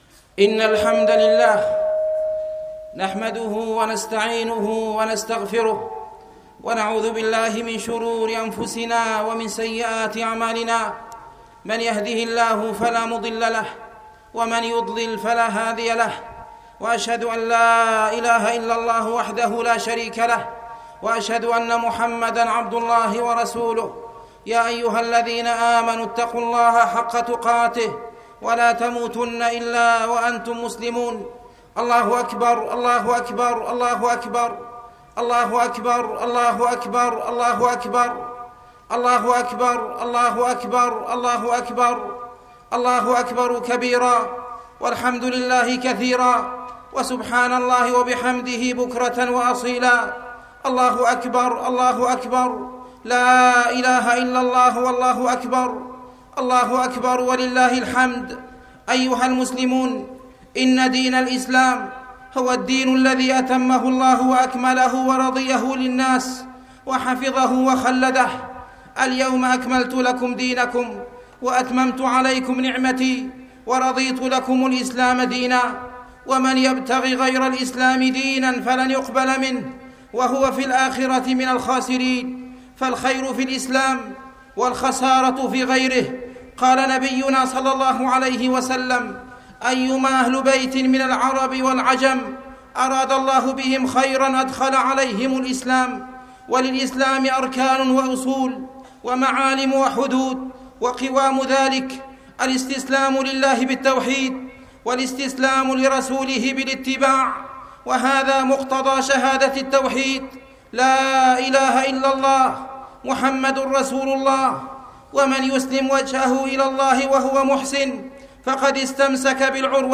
خطبة عيد الفطر 1435
عيد الفطر 1435.mp3